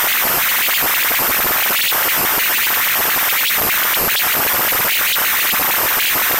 Начало » Записи » Радиоcигналы на опознание и анализ
Бессистемный сигнал
samovozbud.wav